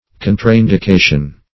Contraindication - definition of Contraindication - synonyms, pronunciation, spelling from Free Dictionary
Contraindication \Con"tra*in`di*ca"tion\, n. (med.)